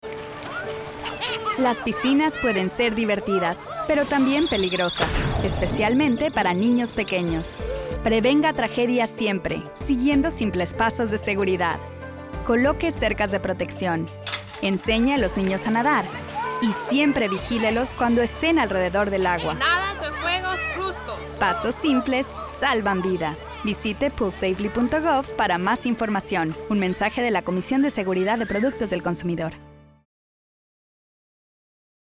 Pool Safely Radio PSAs
Radio-Spot-SPANISH-MIXED-MP3-File.mp3